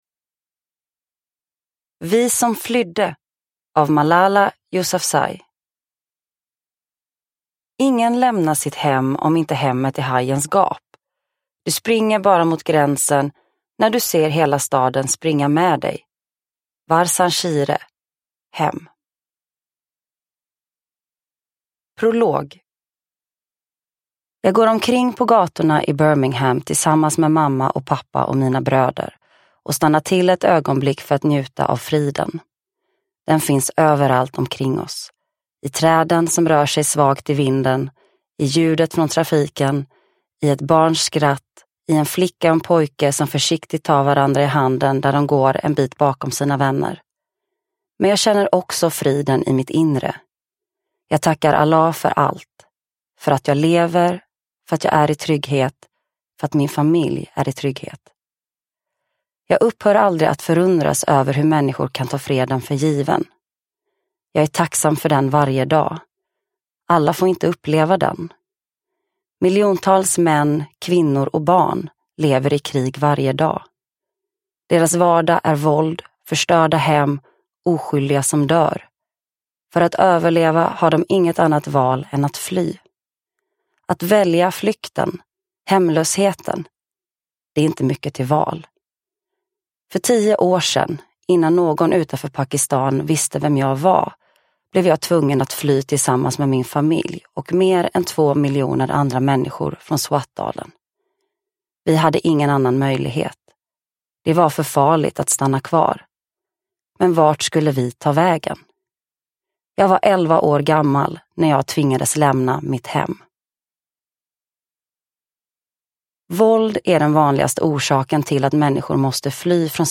Vi som flydde : min egen resa och andra unga kvinnors berättelser om flykt – Ljudbok – Laddas ner